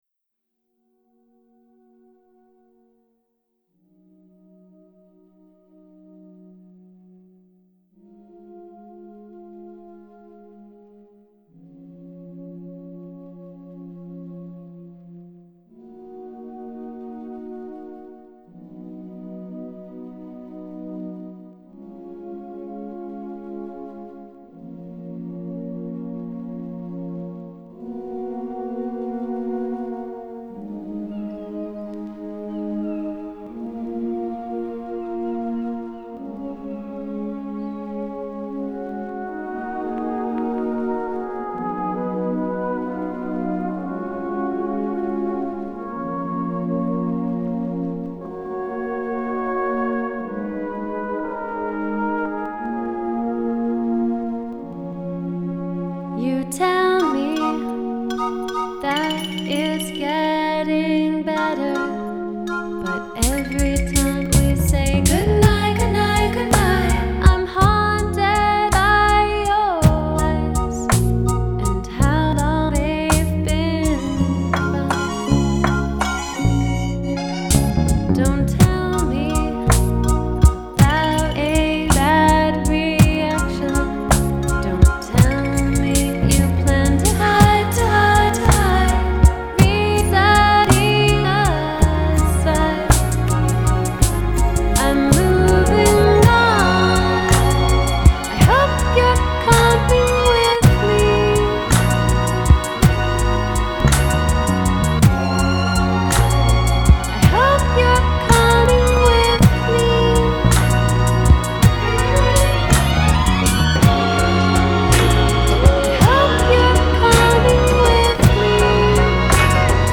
sentimentale valzer Anni Sessanta